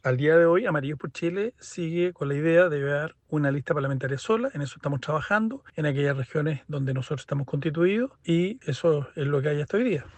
Sin embargo, el presidente de la tienda política, el diputado Andrés Jouannet, ratificó que -en el caso de la parlamentaria- también irán en solitario.